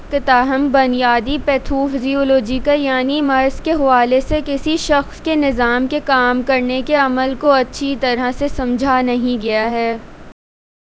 deepfake_detection_dataset_urdu / Spoofed_TTS /Speaker_10 /113.wav